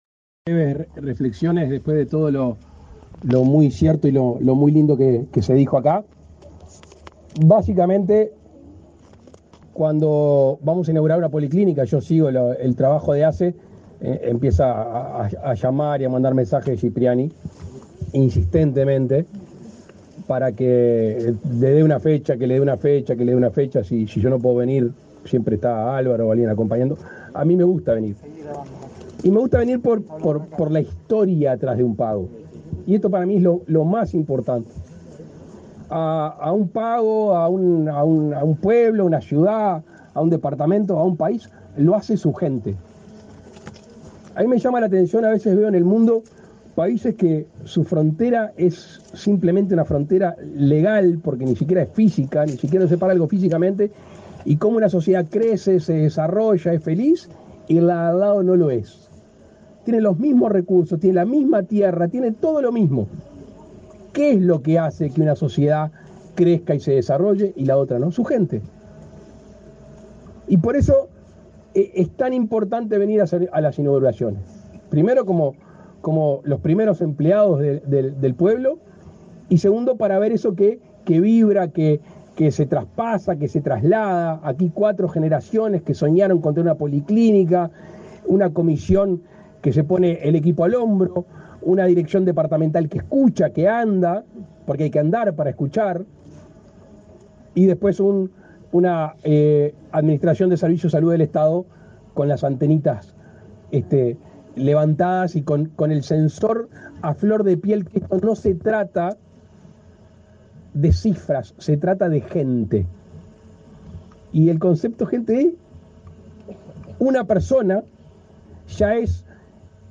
Palabras del presidente de la República, Luis Lacalle Pou
Palabras del presidente de la República, Luis Lacalle Pou 19/05/2023 Compartir Facebook X Copiar enlace WhatsApp LinkedIn El presidente de la República, Luis Lacalle Pou, participó, este 19 de mayo, en la inauguración de la policlínica de ASSE en la localidad de Quiebra Yugos, en el departamento de Tacuarembó.